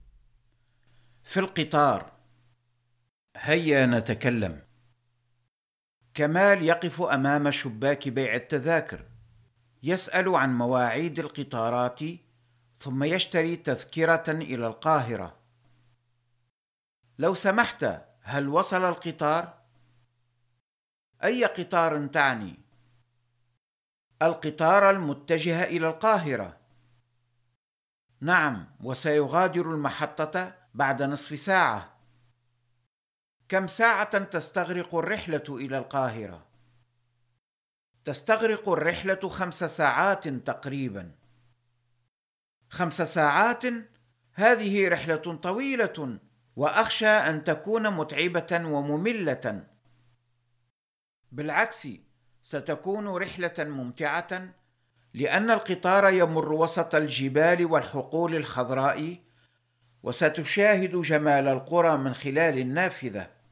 • Enregistrement audio de tout le contenu du manuel de l’élève